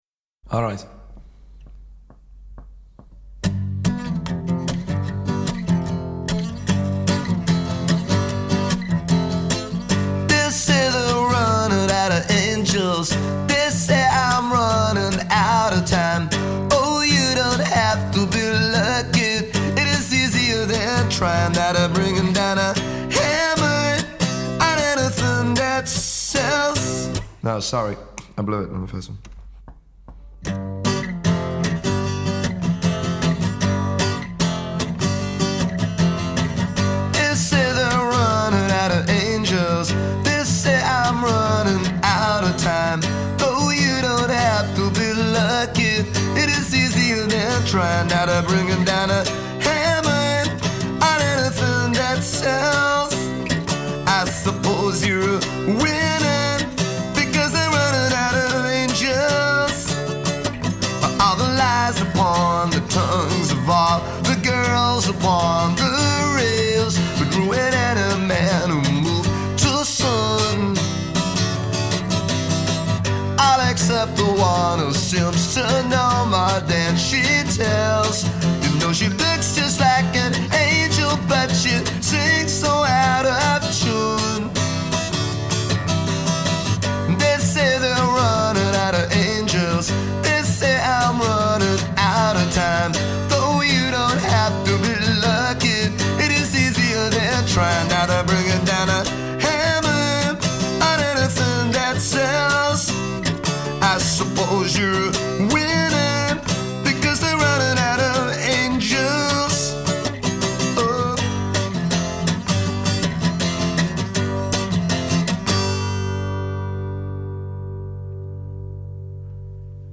acoustic demo tracks